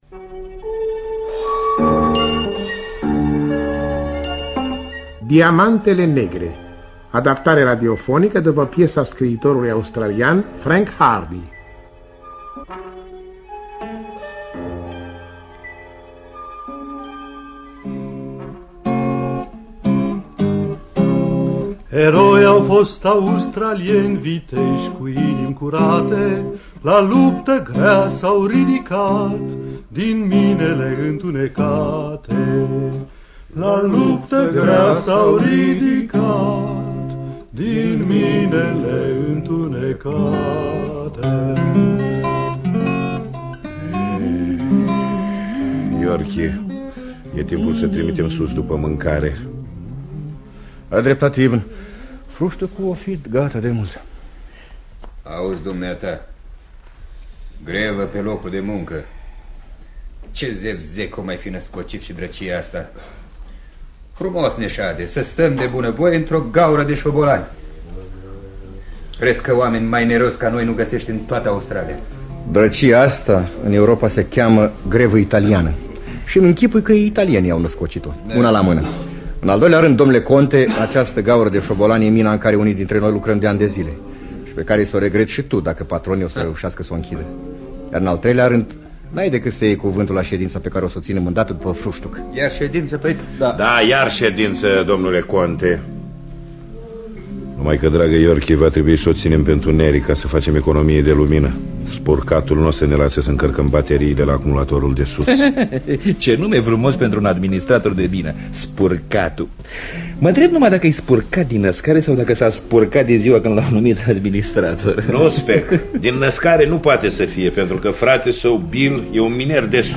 Adaptarea radiofonică de Petre Solomon.
Înregistrare din anul 1966.